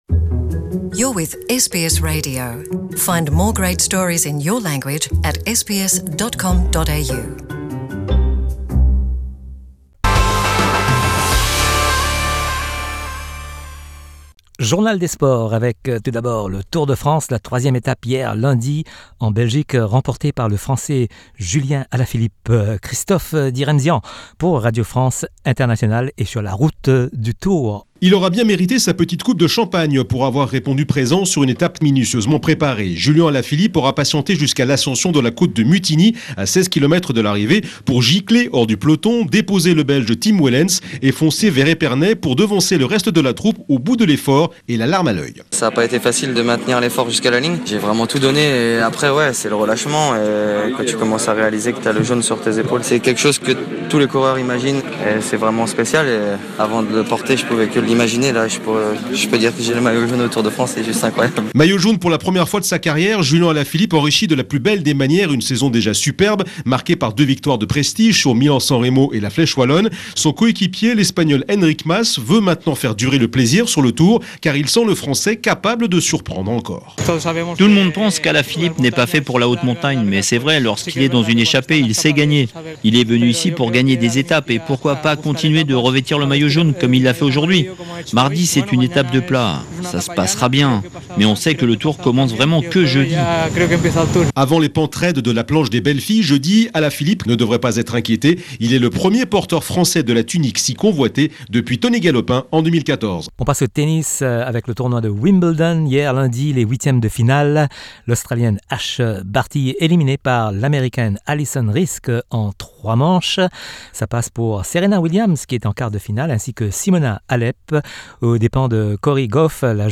Le journal des sports du mardi 9 juillet
L’actualité sportive avec les sonores de RFI.